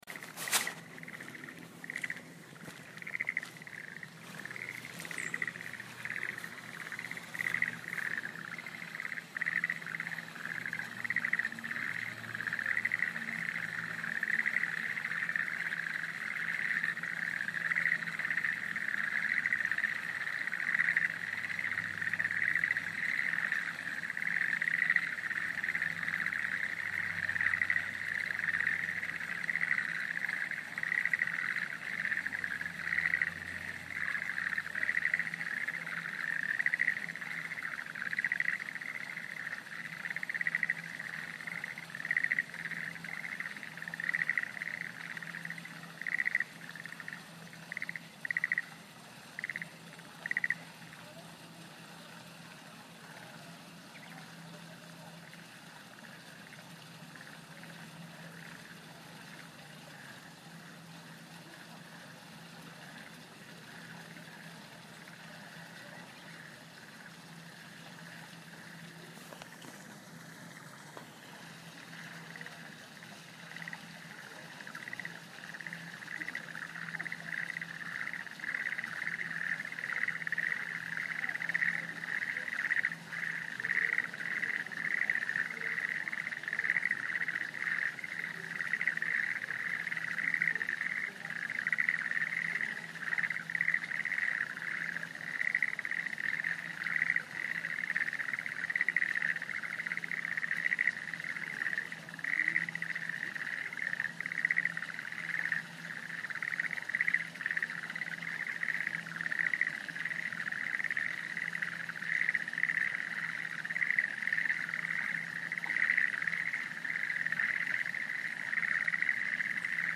かえる 太宰府.mp3　本物のカエルたちの鳴き声　一日中ぼーっと聴いていてもいい